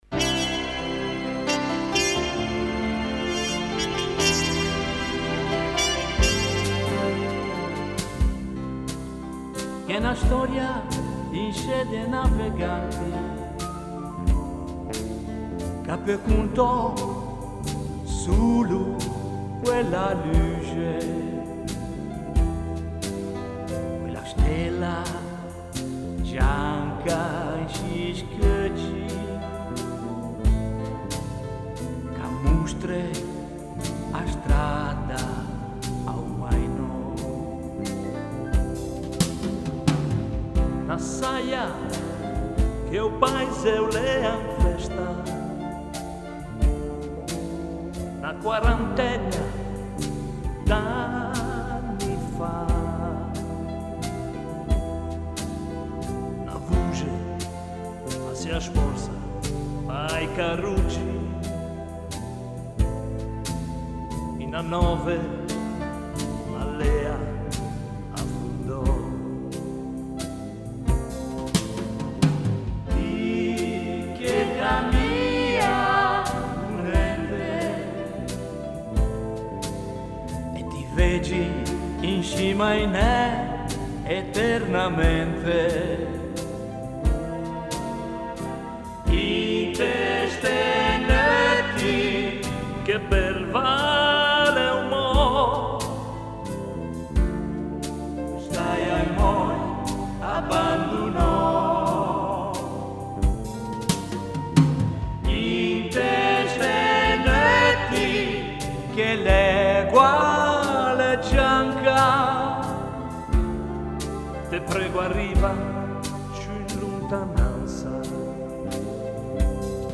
Voce femminile
Brano registrato presso lo studio